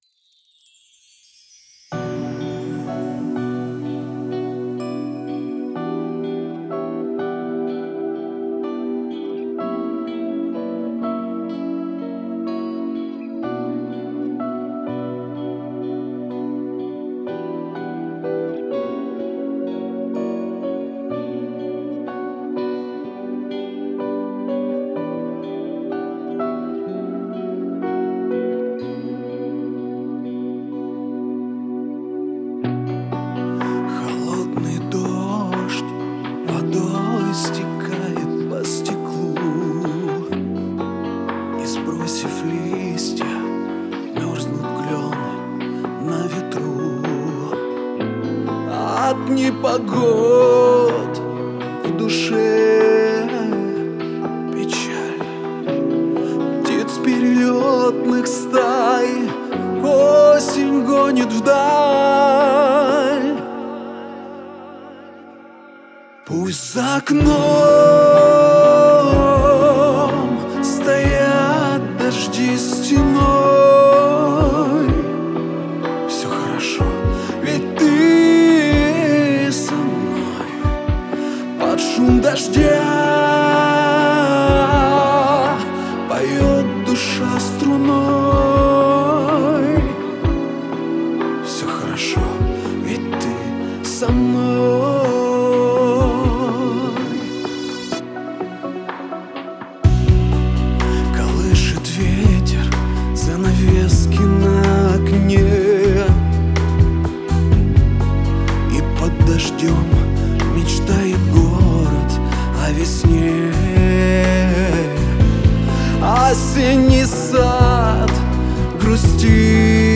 • Жанр: Поп, Русская музыка, Русский поп